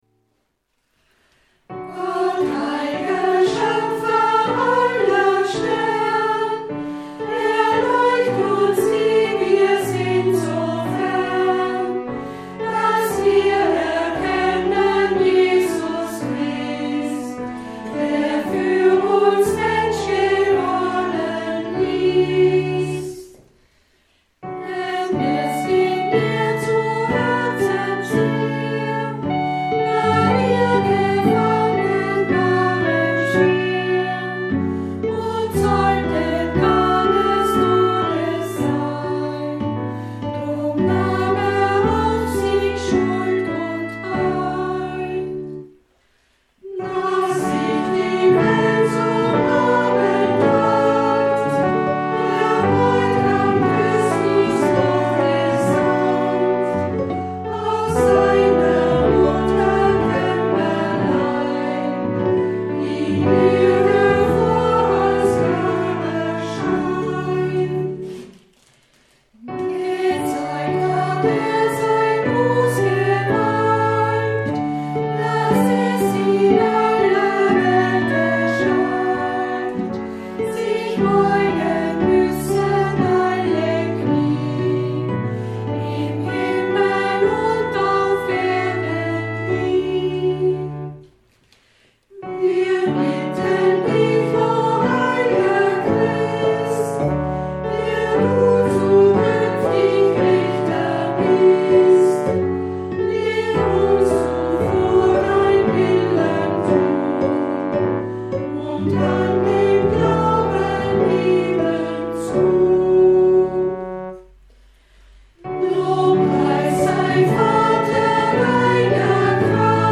Die hier veröffentlichte Hörprobe kann als Studiernhilfe beim Erlernen dieses 1000 Jahre alten Hymnus dienen.
Aufnahme vom Chorsingtag am 10. November 2012 in Friesach Gott